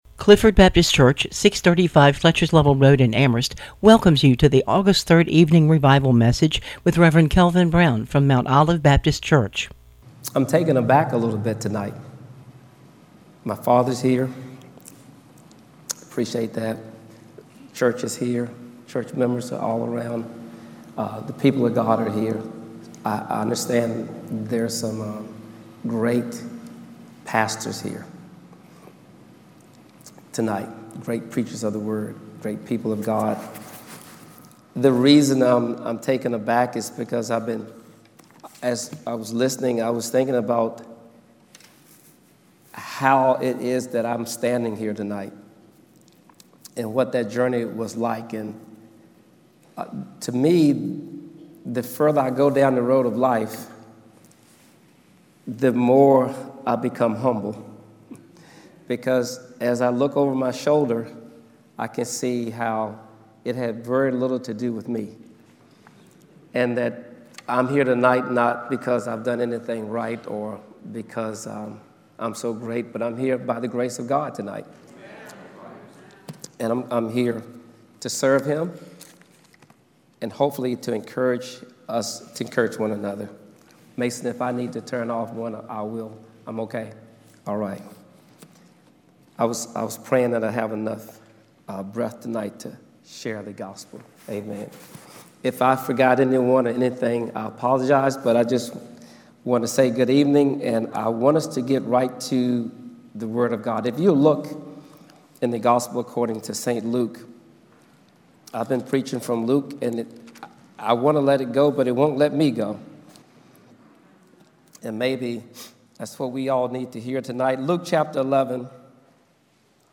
Clifford Baptist Revival Service
PM service